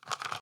* Increased patch packs volume by 4db